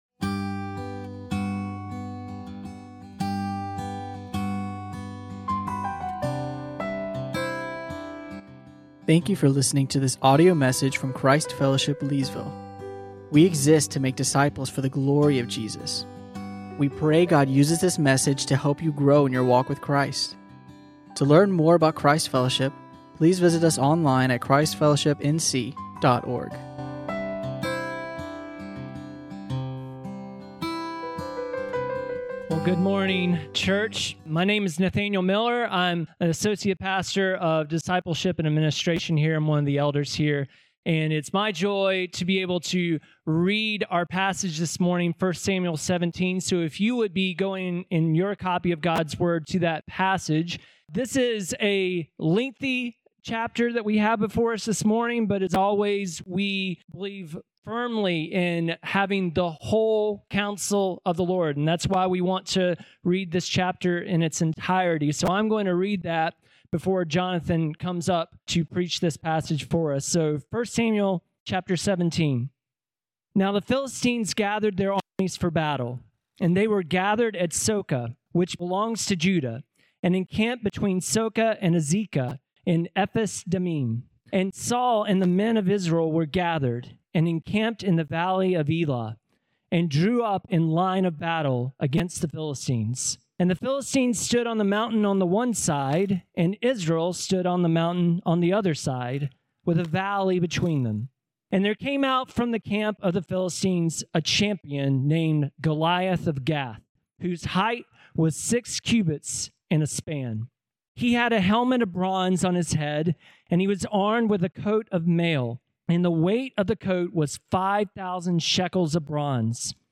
teaches on 1 Samuel 17.